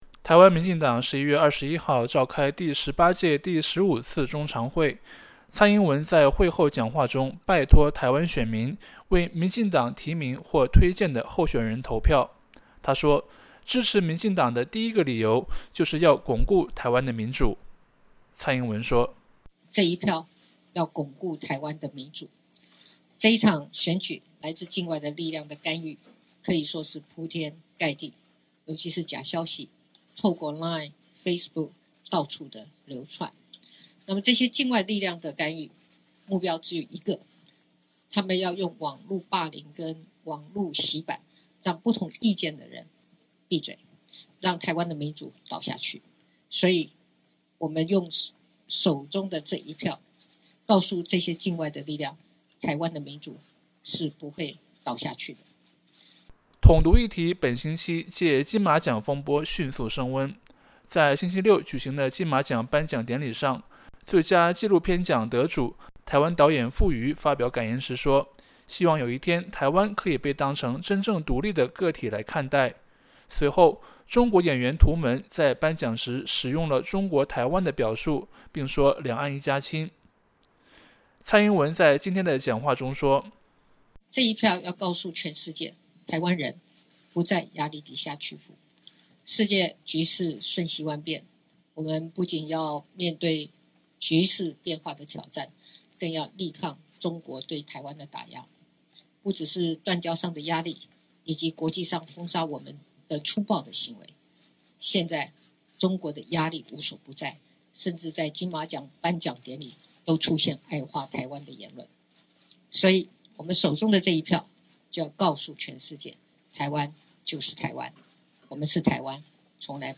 蔡英文选前拉票讲话：台湾从来不是中国台湾